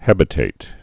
(hĕbĭ-tāt)